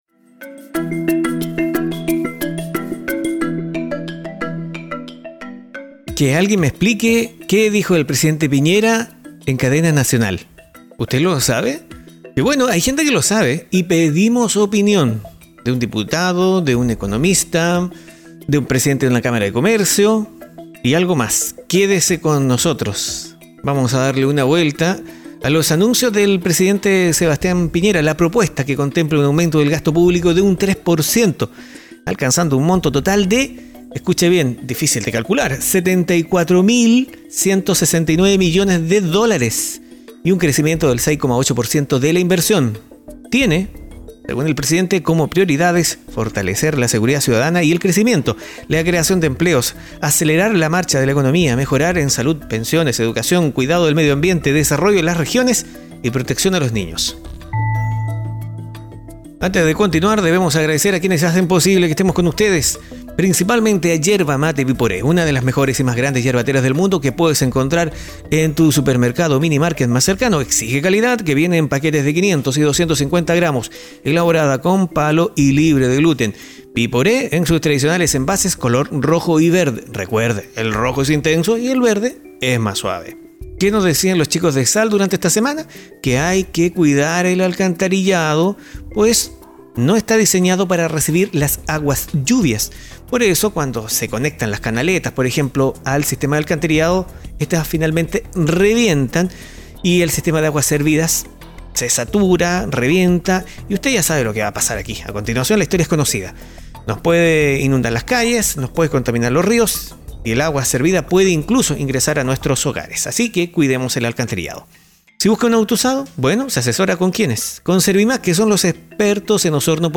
En cadena nacional, el presidente Sebastián Piñera presentó la propuesta que contempla un aumento del gasto público de un 3% alcanzando un monto total de US$ 74.169 millones y un crecimiento del 6,8% de la inversión. Tiene como prioridades fortalecer la seguridad ciudadana y el crecimiento, la creación de empleos, acelerar la marcha de la economía, mejoras en salud, pensiones, educación, cuidado del medio ambiente, desarrollo de las regiones y protección a los niños. Tras los anuncios presidenciales le consultamos su opinión al Diputado Sociaista Fidel Espinoza